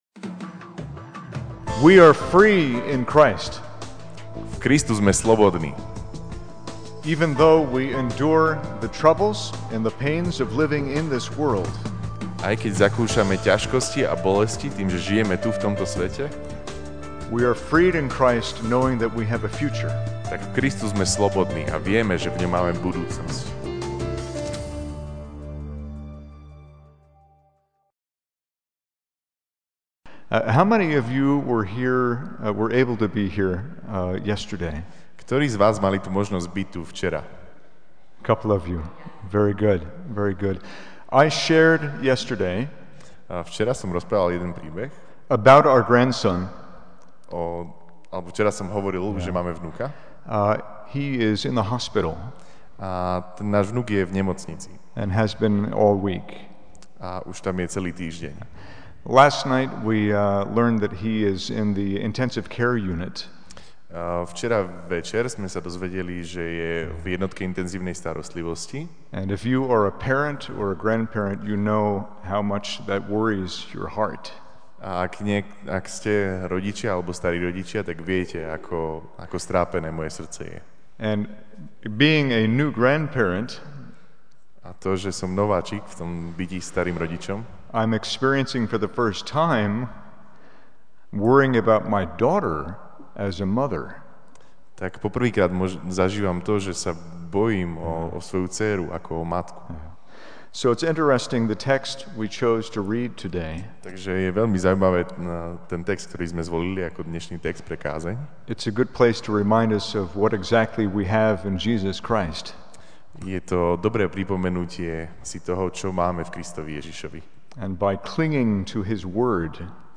MP3 SUBSCRIBE on iTunes(Podcast) Notes Sermons in this Series Večerná kázeň: V Kristu sme slobodní!